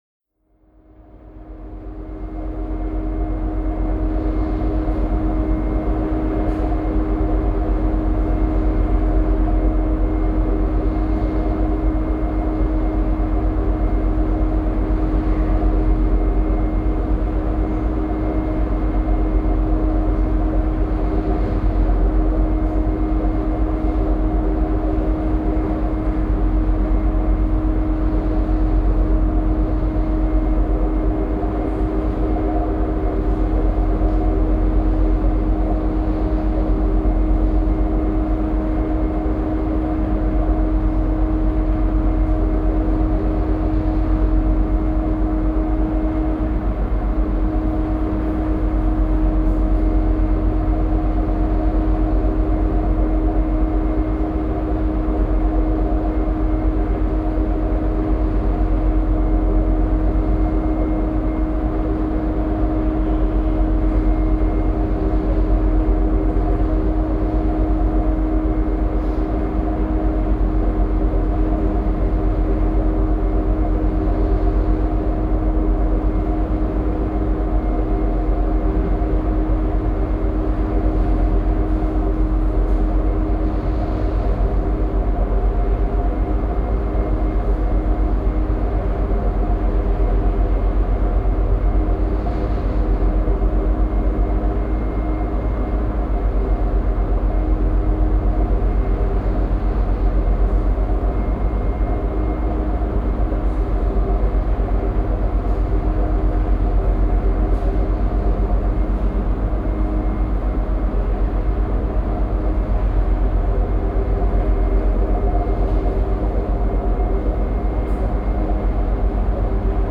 Recorded at 24/48 kHz
This is a soundscape work